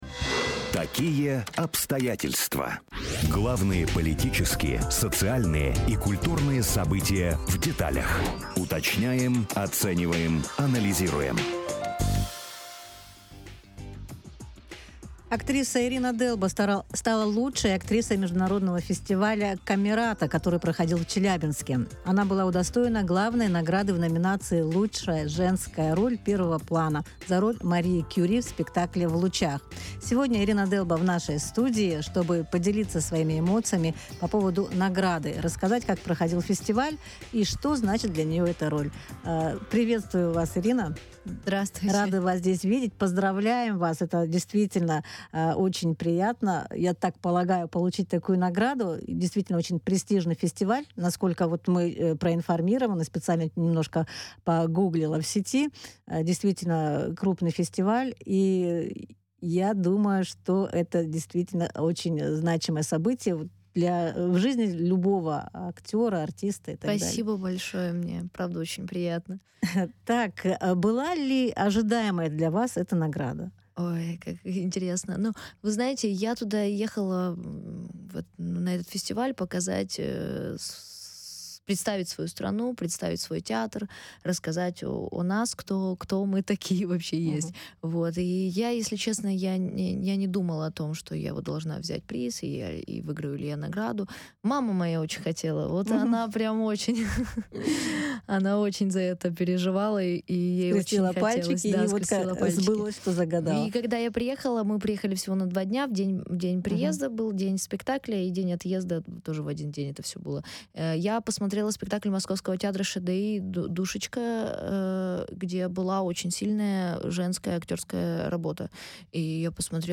Лучшая женская роль первого плана: интервью